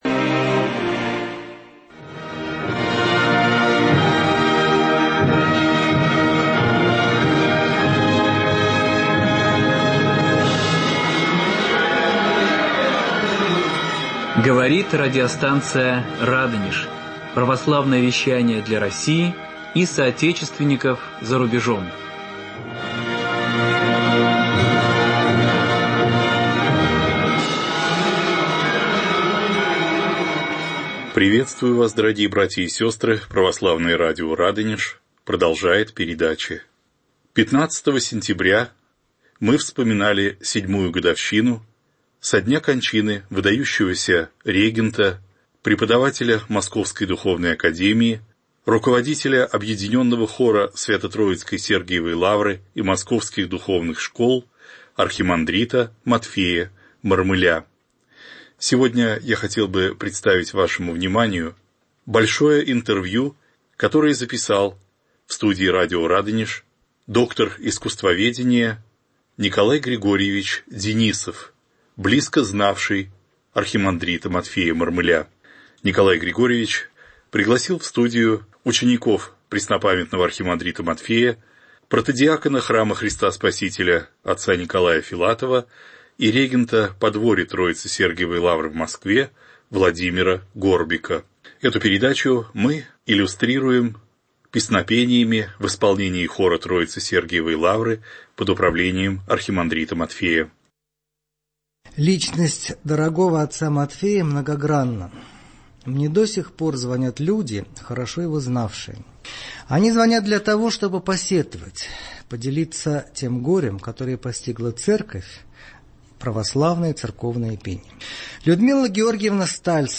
Памяти архимандрита Матфея (Мормыля), регента Объединенного хора Троице-Сергиевой Лавры и Московских духовных школ. Интервью с учениками отца Матфея.